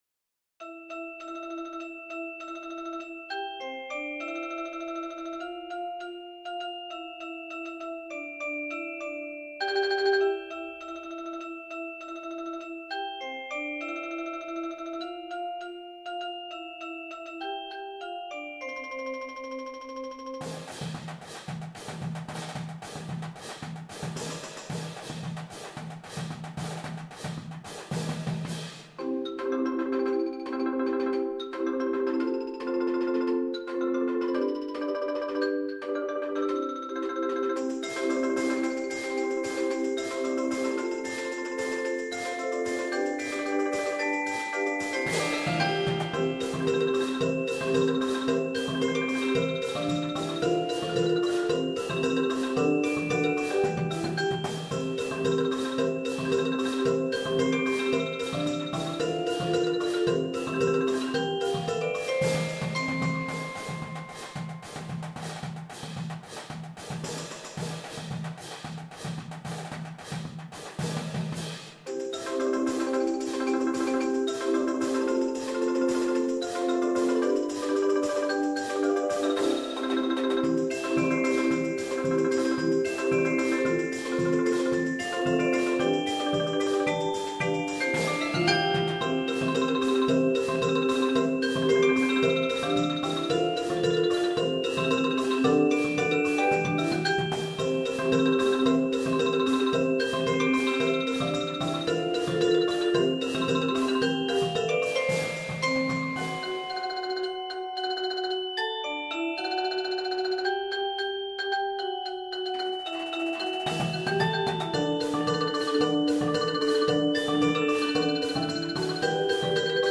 Mallet-Steelband